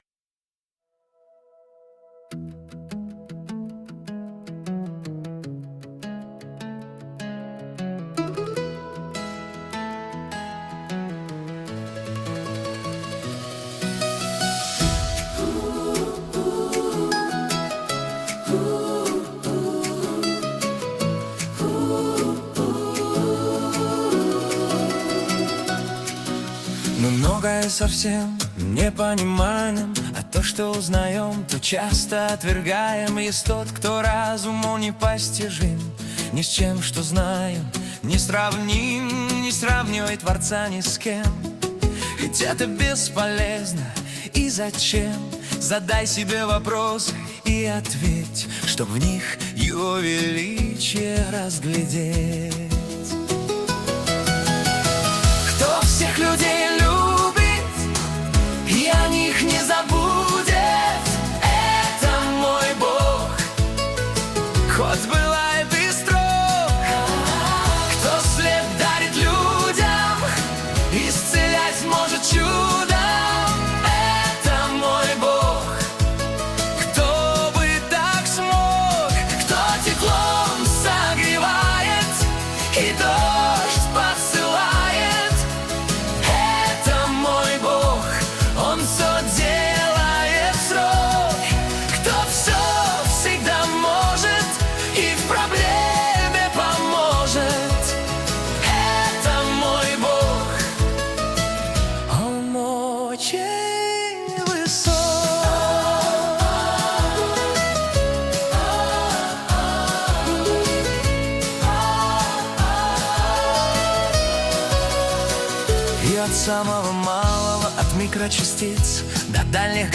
E/C#m/E/A/Hsus4/E/C#m/A/Hsus4/H
127 просмотров 829 прослушиваний 59 скачиваний BPM: 78